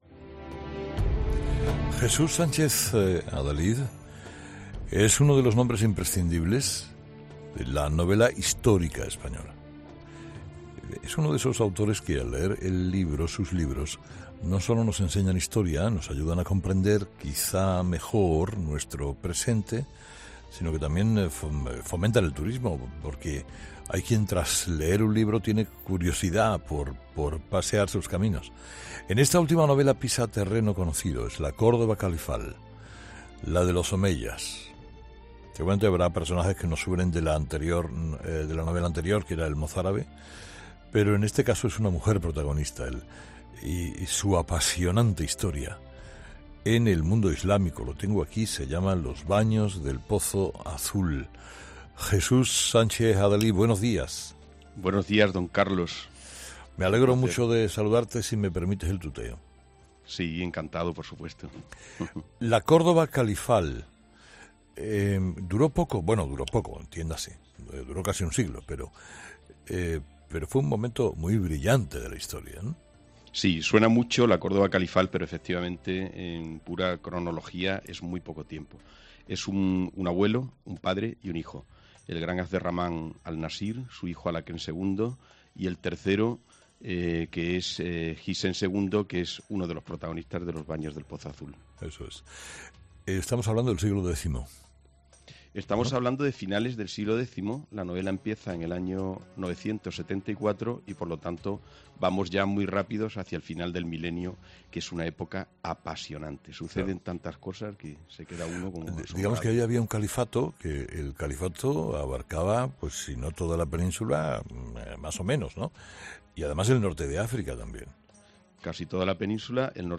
AUDIO: El escritor Jesús Sánchez Adalid presenta en Herrera en COPE su última obra "Los baños del pozo azul"